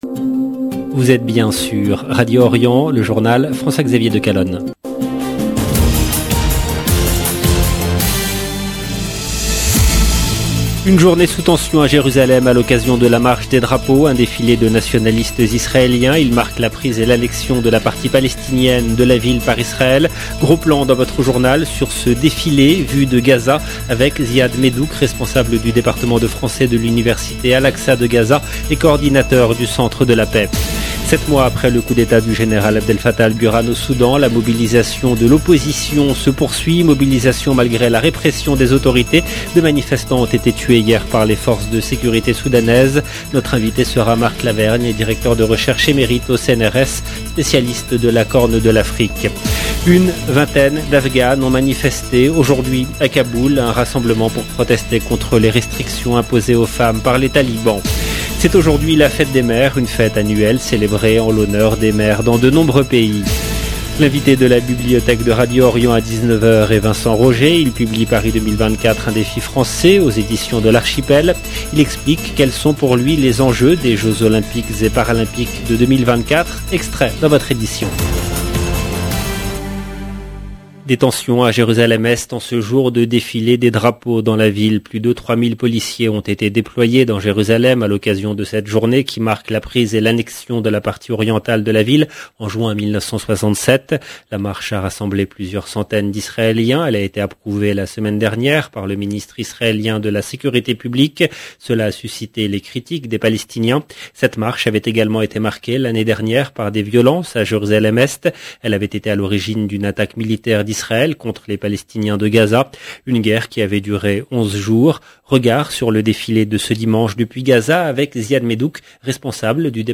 LE JOURNAL DU SOIR EN LANGUE FRANCAISE DU 29/5/2022